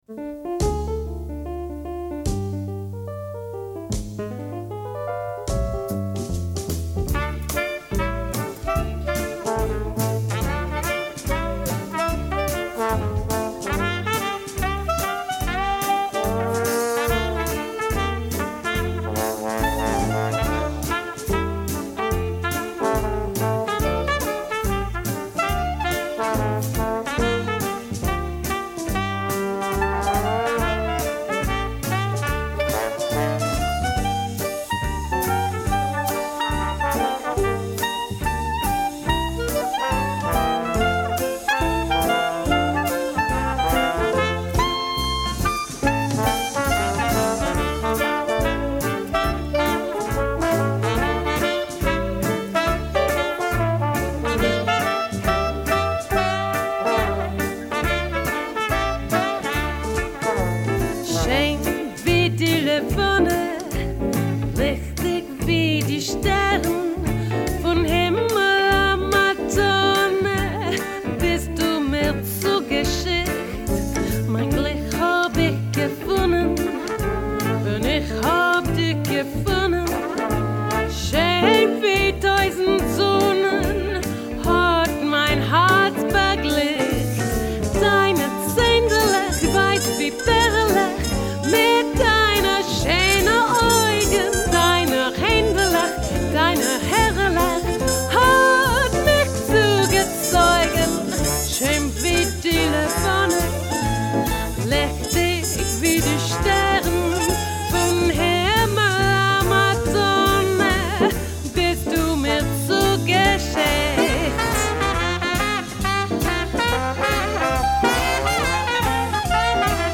Yiddish & Klezmer